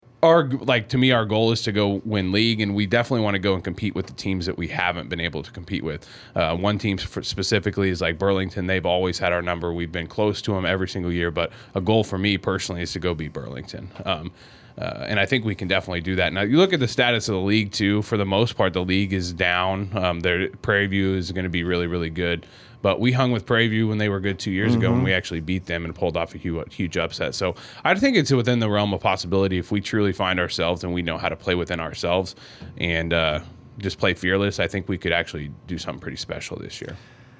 conducting the interview